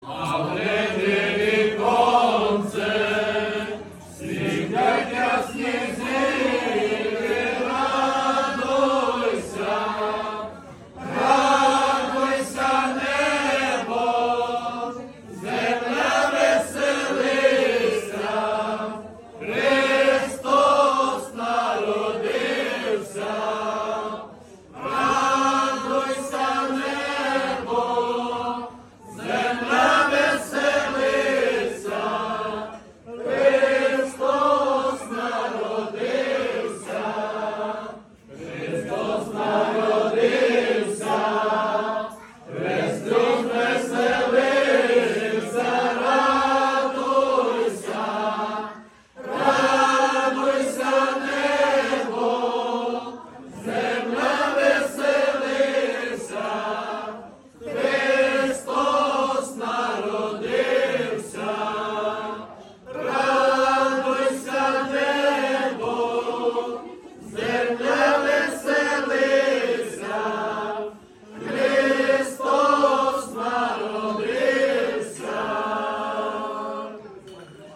Радуйся небо. Хор прихожан храму.
koladkanatrapeze_radujsia.mp3